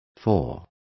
Complete with pronunciation of the translation of four.